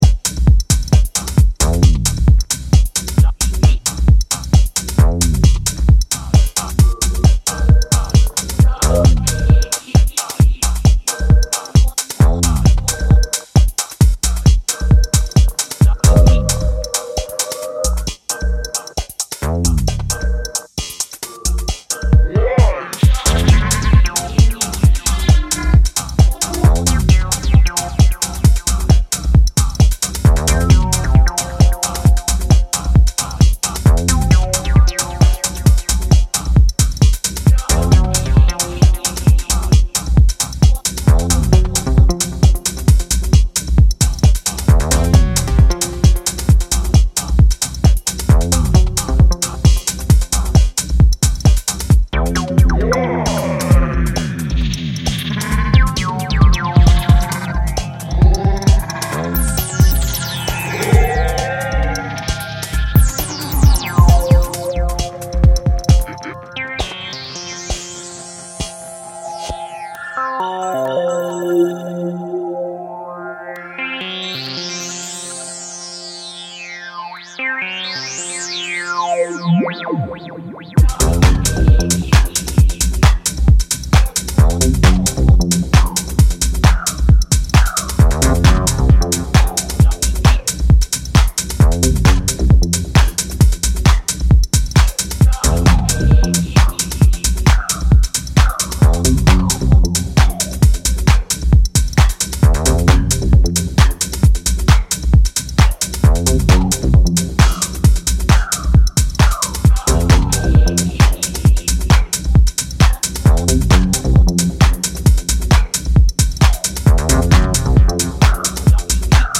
maintaining all the fun for a heads down shuffle.